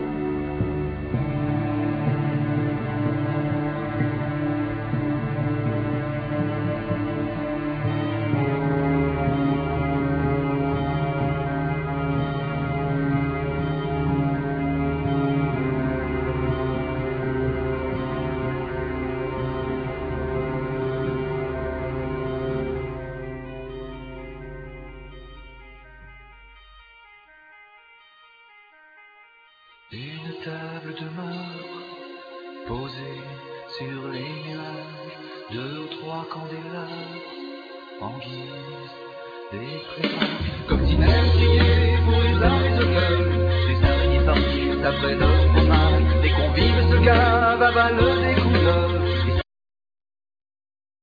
Violin,Viola,Guitar,Vocals
Keyboards,Backing vocals
Drums,Percussions
Ac.Guitar,Bass
English horn
Cello
Flute